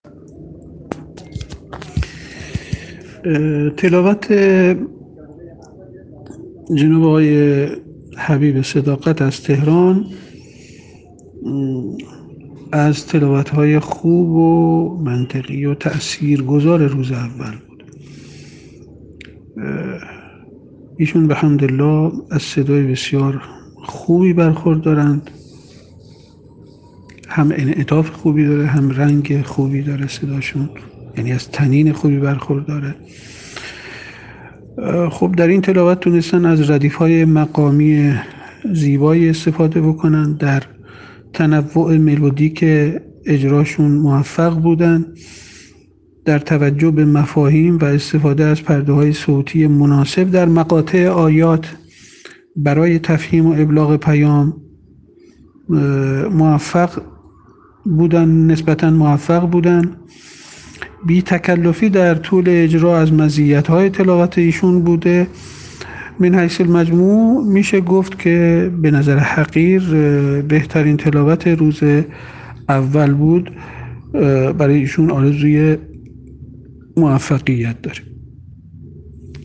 ایشان از صدای بسیار خوبی برخوردارند و انعطاف، رنگ و طنین خوبی در صدای ایشان دیده می‌شود.